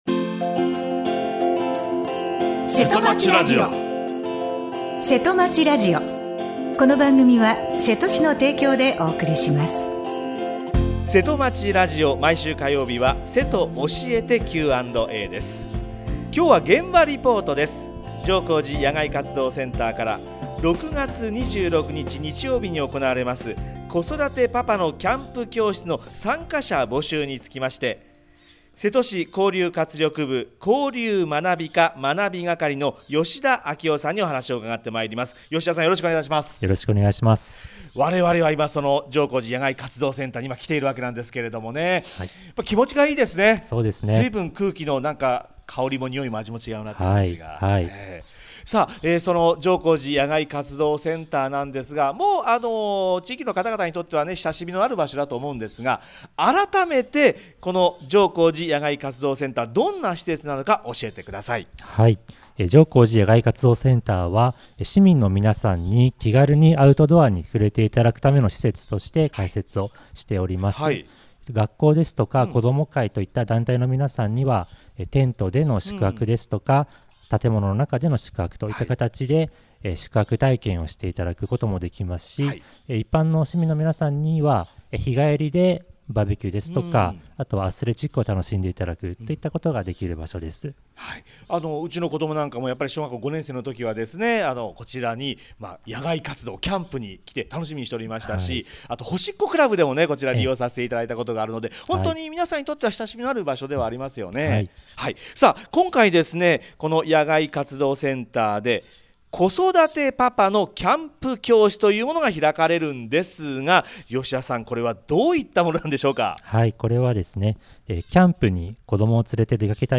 28年5月17日（火） 毎週火曜日は「せとおしえてQ&A」。 今日は、現場リポートです。